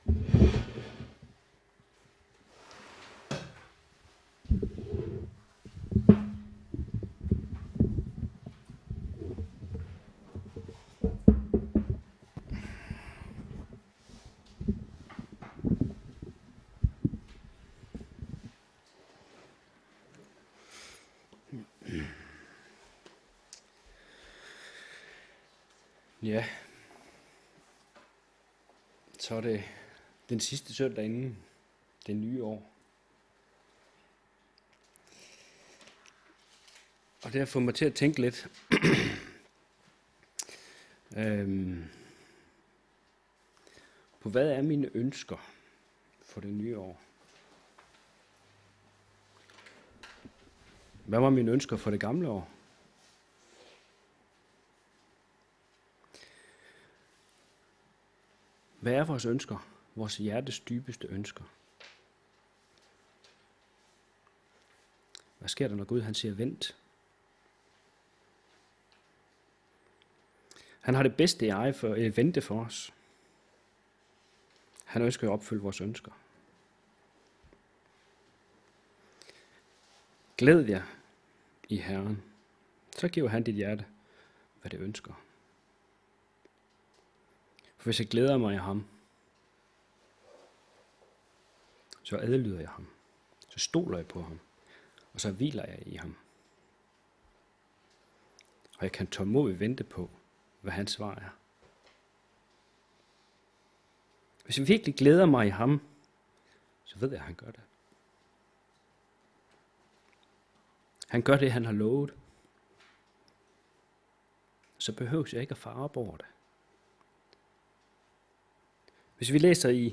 Talen er inspireret ud fra Salme 37:1-11